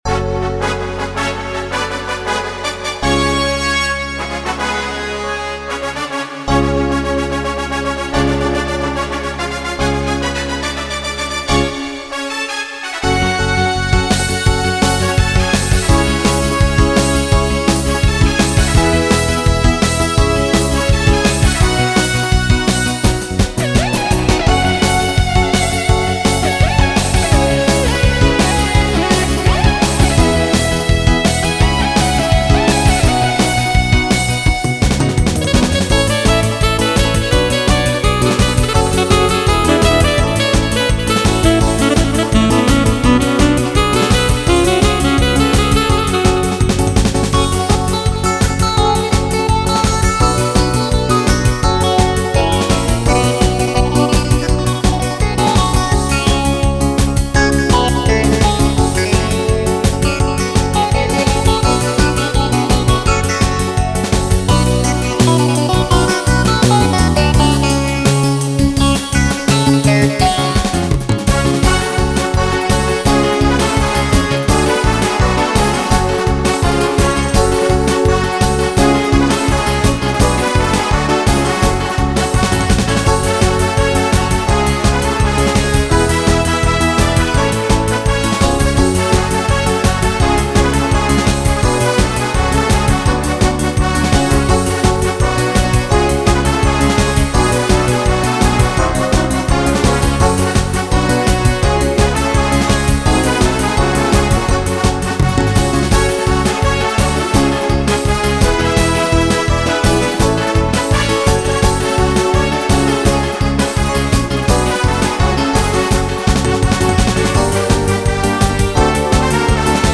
00 Freilach Rock
ז'אנרFreilach
BPM168